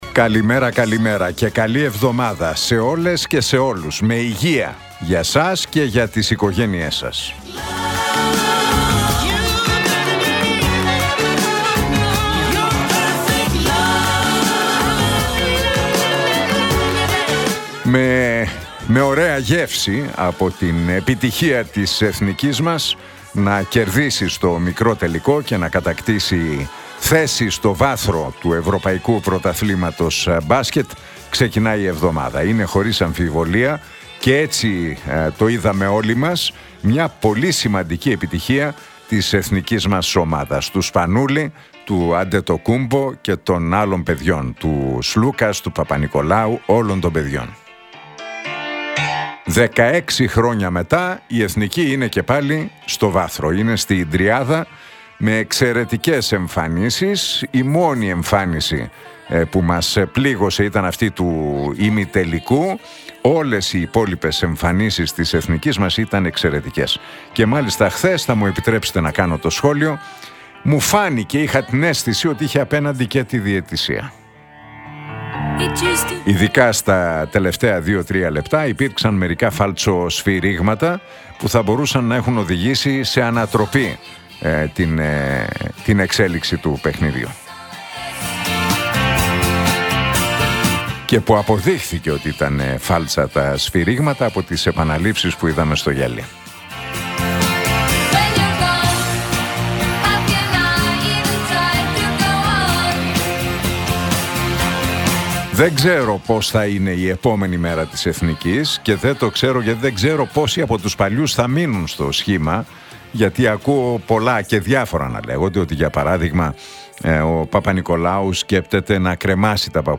Ακούστε το σχόλιο του Νίκου Χατζηνικολάου στον ραδιοφωνικό σταθμό Realfm 97,8, την Δευτέρα 15 Σεπτεμβρίου 2025.